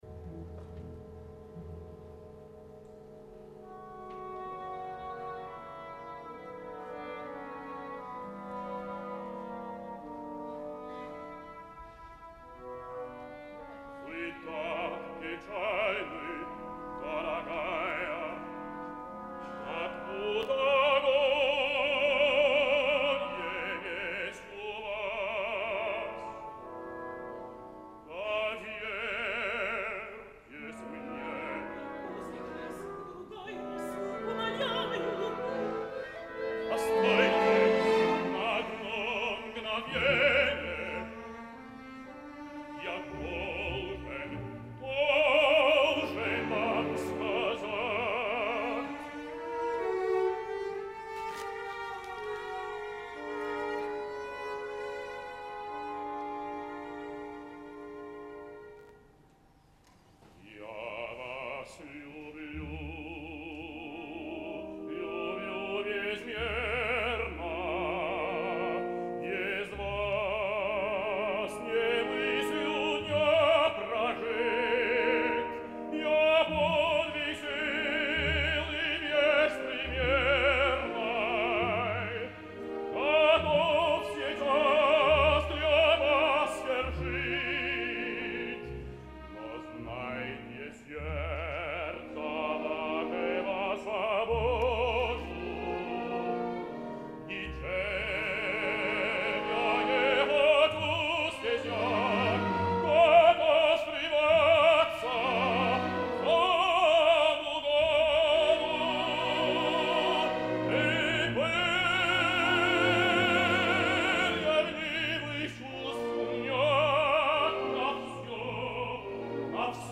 Ara us deixaré la versió en directa de la famosa ària (amb el corresponent recitatiu inicial que vaig obviar per la tria), però aquest cop procedent de la representació del 18 d’octubre de 1999 de Pikovaia Dama a l’Opéra de Paris, on Keenlyside cantava el rol de Comte Yeletsky i on podreu també escoltar una frase de la Lisa de Karita Mattila.